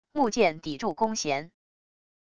木箭抵住弓弦wav音频